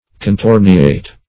contorniate - definition of contorniate - synonyms, pronunciation, spelling from Free Dictionary
Contorniate \Con*tor"ni*ate\, Contorniate \Con*tor"ni*a`te\, n.,